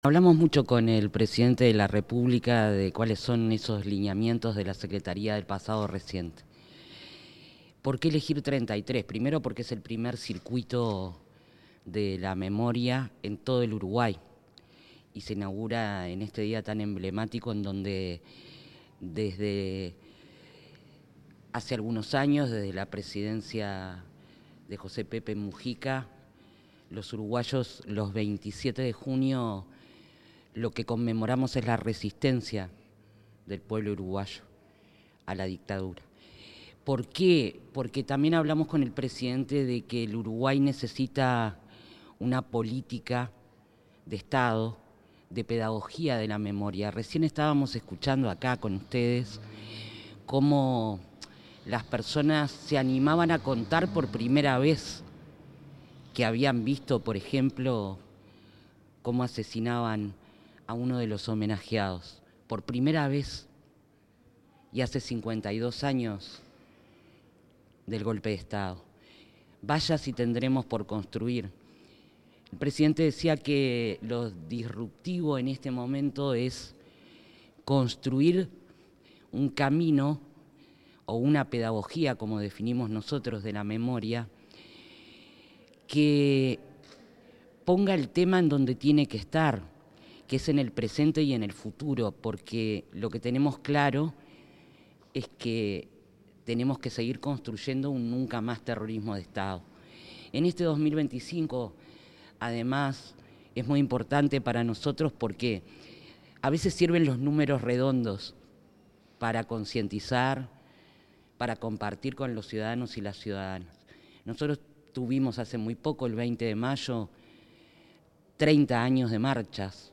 Declaraciones de la directora de la Secretaría de Derechos Humanos para el Pasado Reciente, Alejandra Casablanca
En el marco de la sesión abierta de la Comisión Nacional Honoraria de Sitios de Memoria, donde se abordaron diversos aspectos sobre derechos humanos,